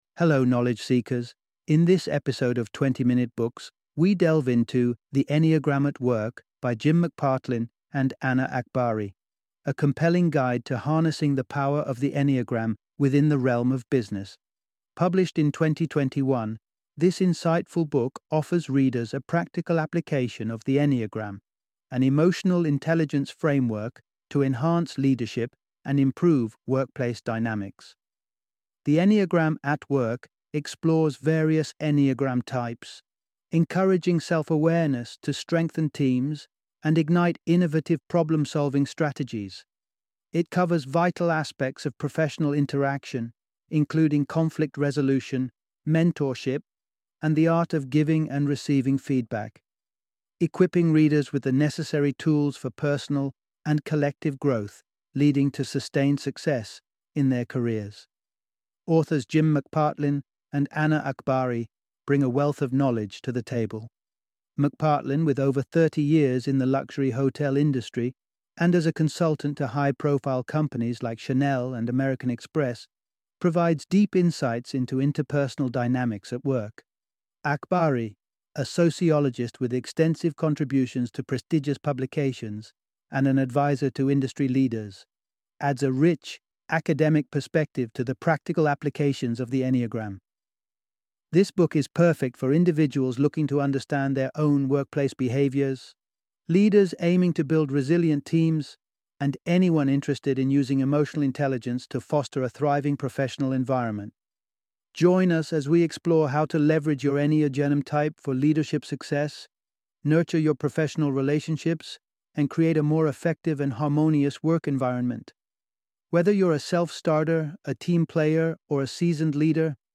The Enneagram at Work - Audiobook Summary